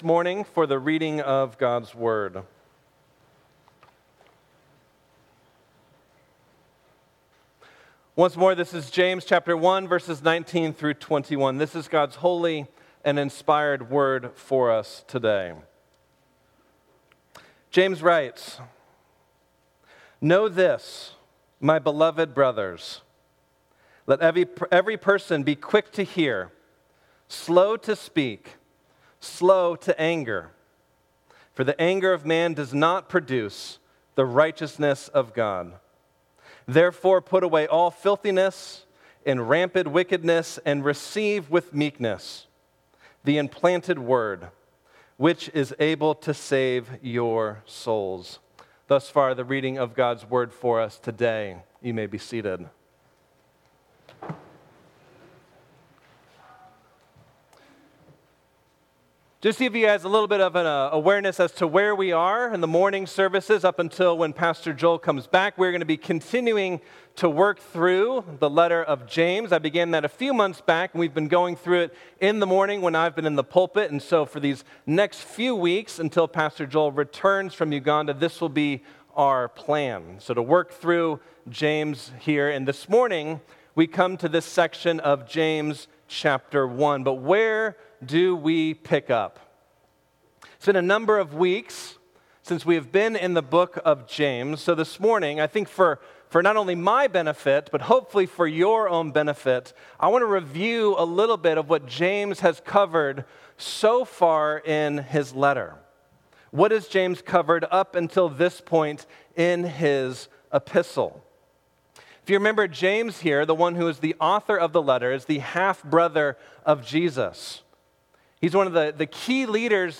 Sermons | New Life Presbyterian Church of La Mesa